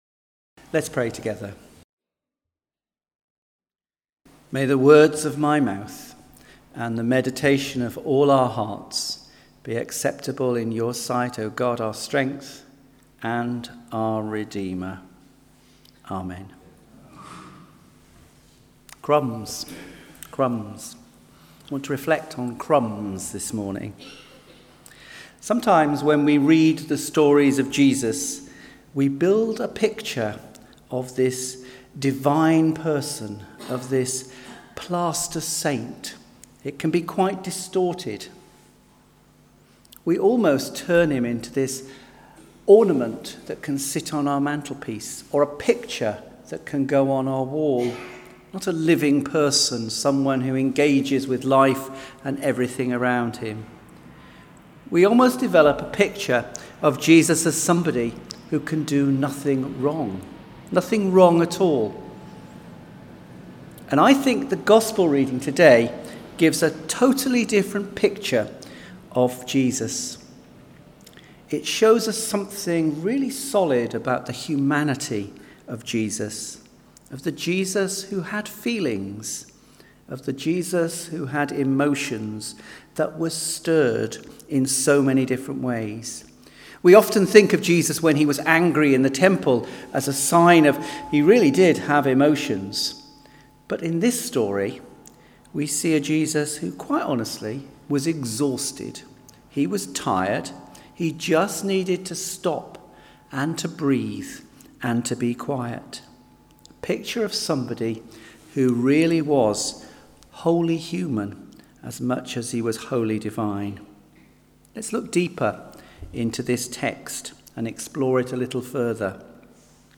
Readings James 2: 1-17 Mark 7: 24-30 Sermon Text Sometimes when we read the stories of Jesus we begin to build a picture of Jesus and his divinity.